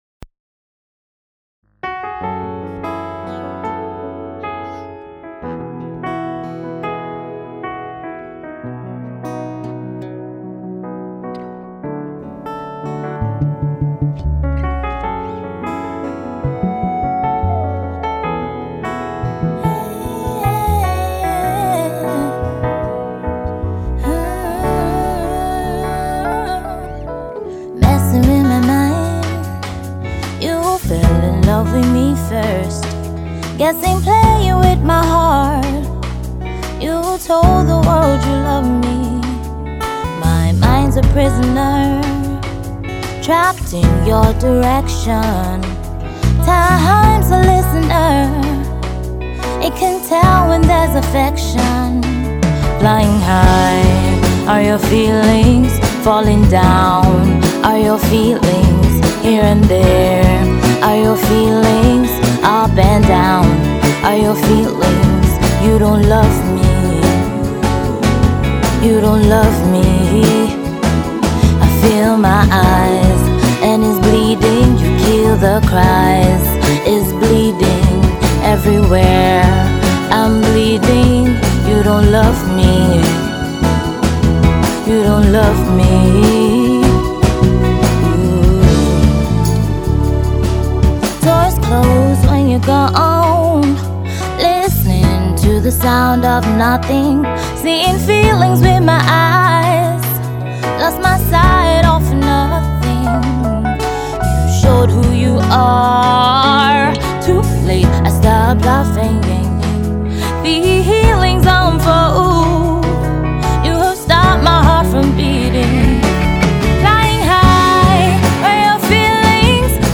is a beautiful soft melody, that will sooth your ears.